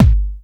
Kick_93.wav